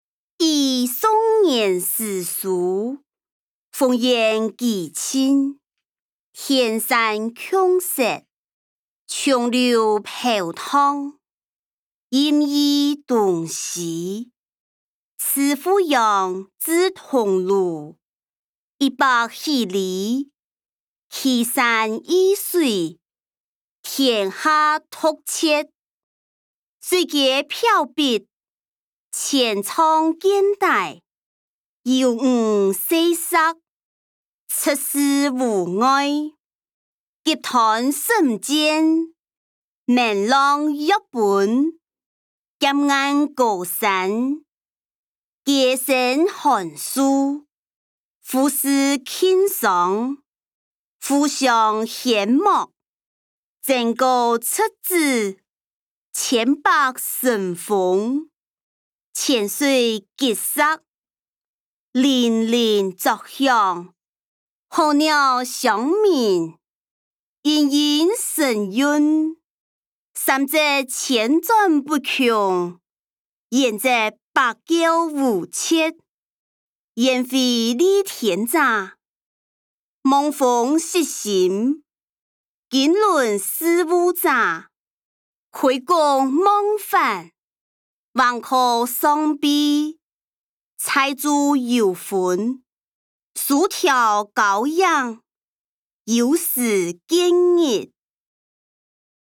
歷代散文-與宋元思書音檔(四縣腔)